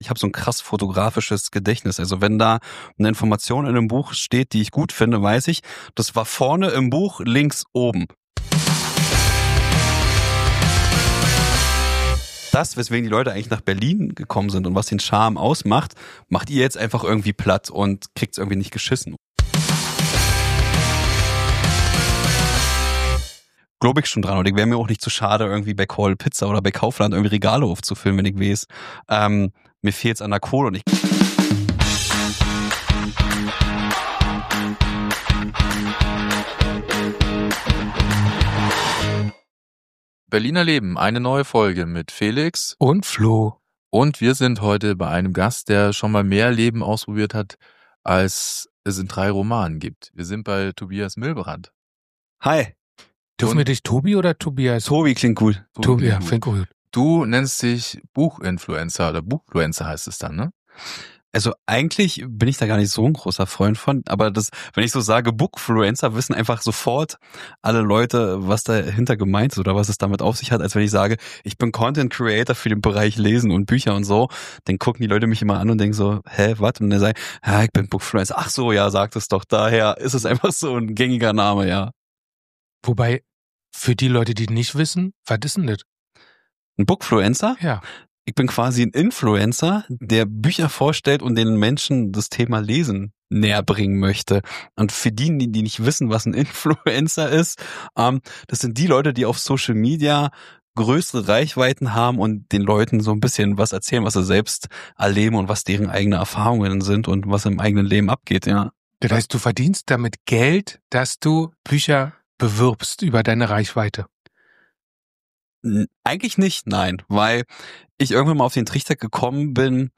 Aufgenommen haben wir diese Folge in seinem Büro und Podcaststudio, umgeben von meterhohen Bücherregalen.
Dazu kommen Gespräche über Religion, Techno, die Berliner Clublandschaft und darüber, dass der Ton in Berlin rauer geworden ist. Es wird viel gelacht, auch mal diskutiert und zum Schluss schlagen wir sogar den Bogen zur IFA, inklusive eines besonderen Geschenks aus der vorherigen Folge.